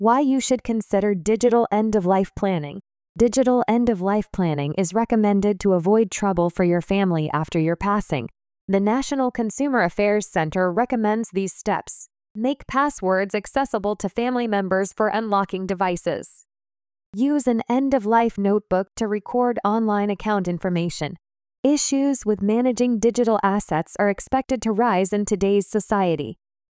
【ナチュラルスピード】